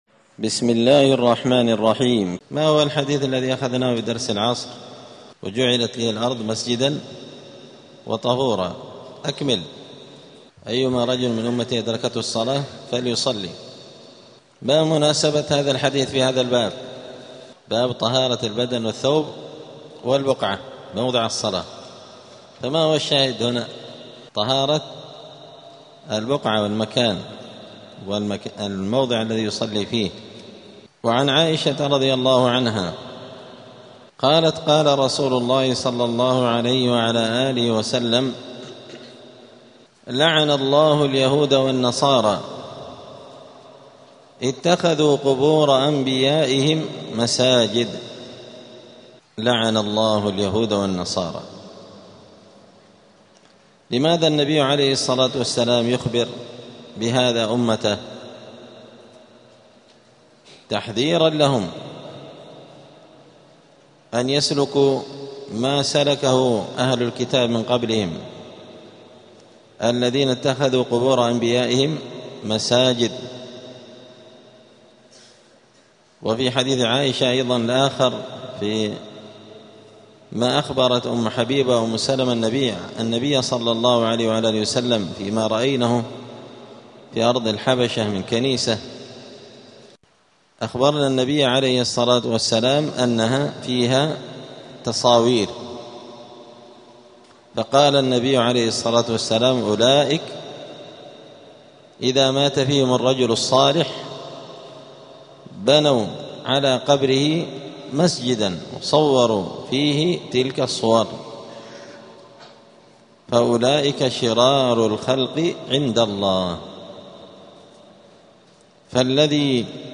دار الحديث السلفية بمسجد الفرقان قشن المهرة اليمن
*الدرس الواحد والسبعون بعد المائة [171] باب طهارة البدن والثوب {تحريم بناء المساجد على القبور}*